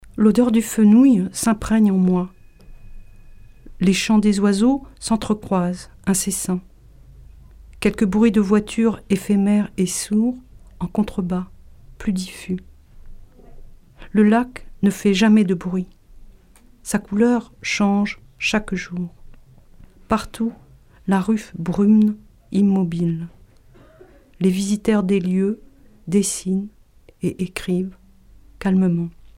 4-Oiseau-Grand-Site.mp3